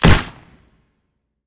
sci-fi_weapon_pistol_shot_01.wav